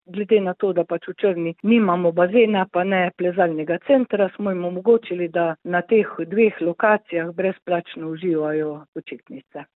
izjava zupanja Lesjak 3 ZA SPLET.mp3